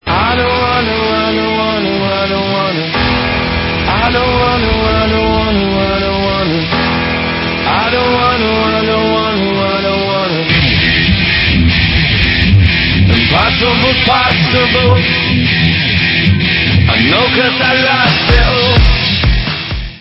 sledovat novinky v kategorii Dance